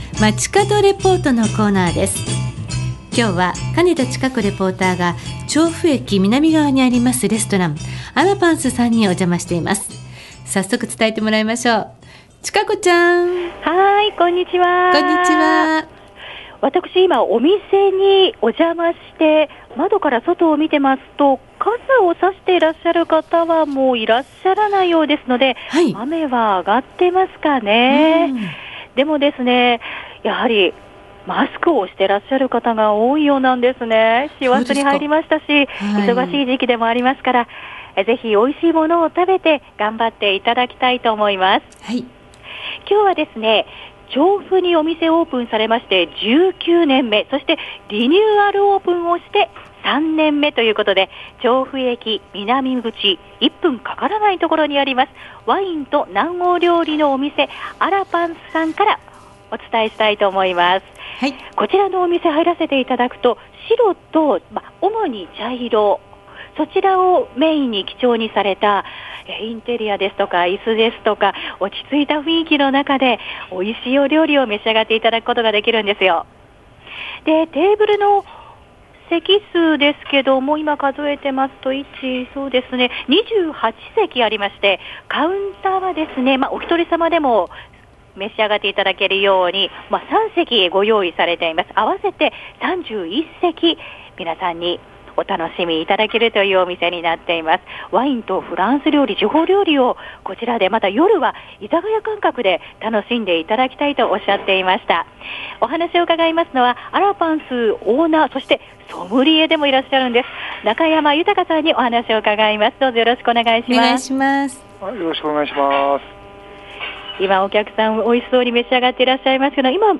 月曜日の街角レポート
アラパンス ↑とても気さくに話をしてくださったので、音声でお楽しみください。